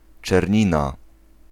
Czernina (Polish pronunciation: [t͡ʂɛrˈɲina]
Pl-czernina.ogg.mp3